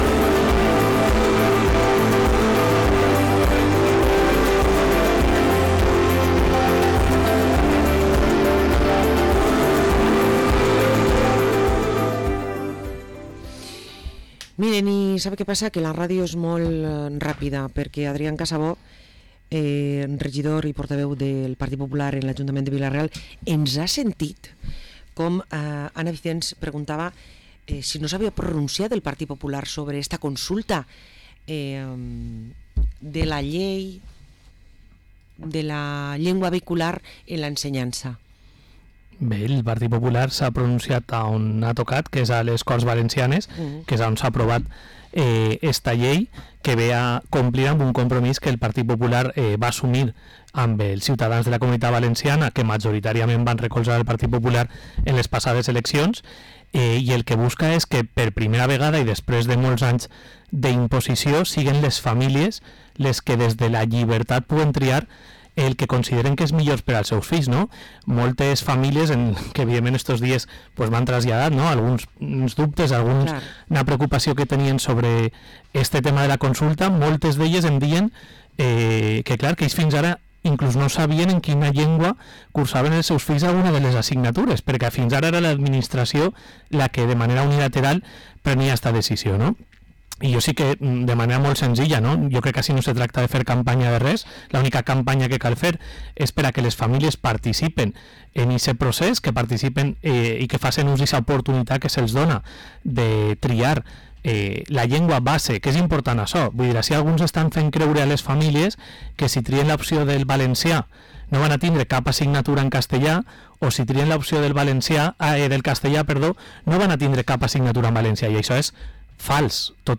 Parlem amb Adrián Casabó, regidor del PP a l´Ajuntament de Vila-real